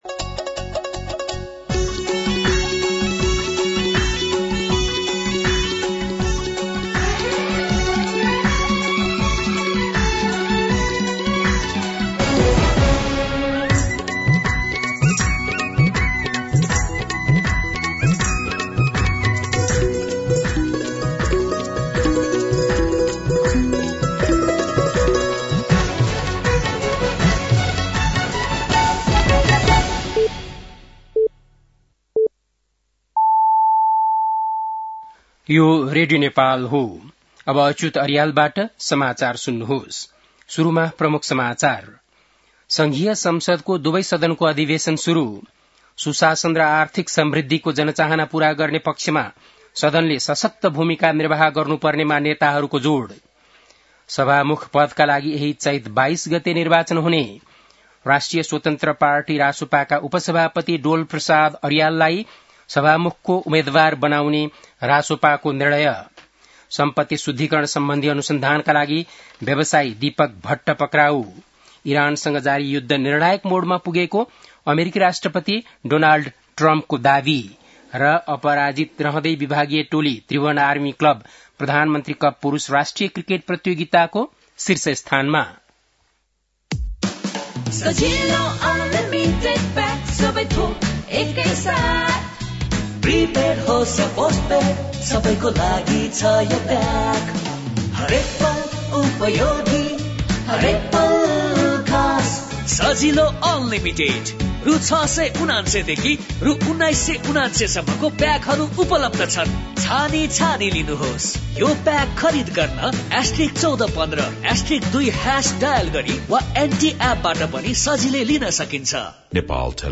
बेलुकी ७ बजेको नेपाली समाचार : १९ चैत , २०८२
7-pm-news.mp3